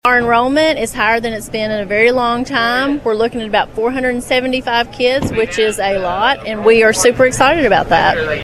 told Your News Edge during a live broadcast